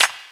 • Studio Clap Sound Clip B Key 04.wav
Royality free clap - kick tuned to the B note. Loudest frequency: 3578Hz
studio-clap-sound-clip-b-key-04-sio.wav